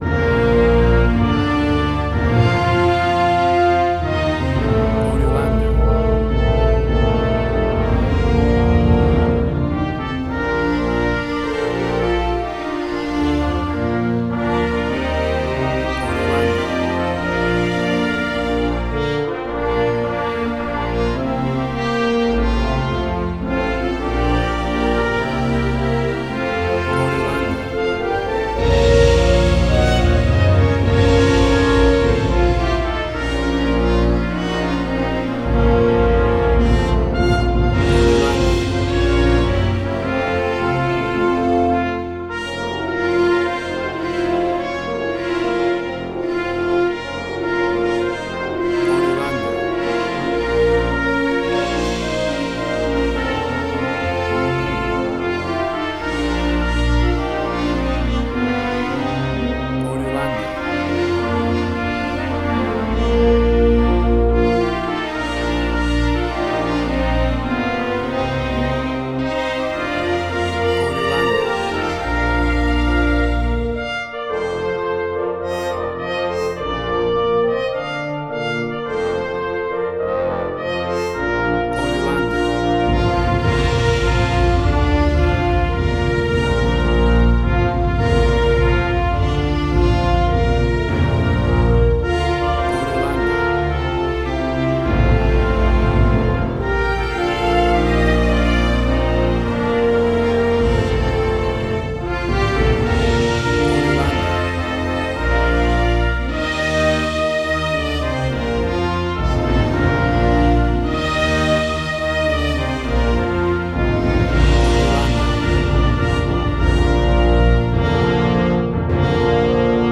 A vibrant and heartwarming traditional version
WAV Sample Rate: 16-Bit stereo, 44.1 kHz